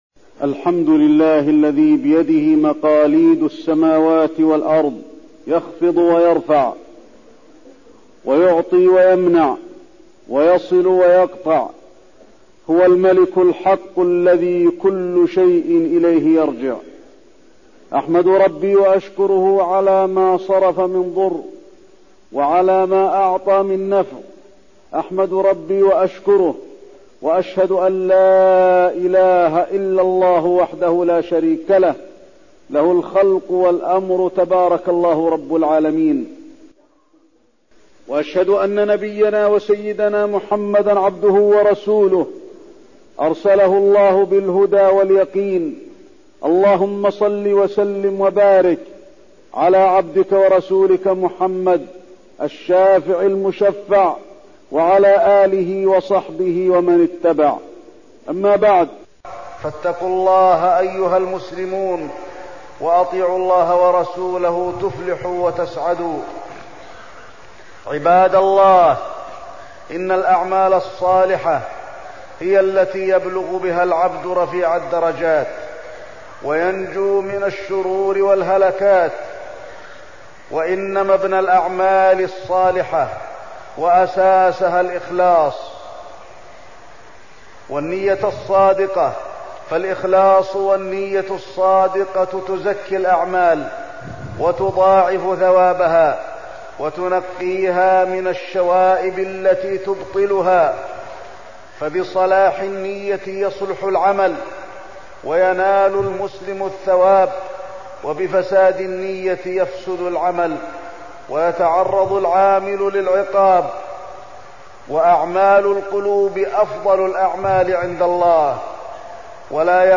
تاريخ النشر ١٣ ذو الحجة ١٤١٥ هـ المكان: المسجد النبوي الشيخ: فضيلة الشيخ د. علي بن عبدالرحمن الحذيفي فضيلة الشيخ د. علي بن عبدالرحمن الحذيفي التقوى وإخلاص العمل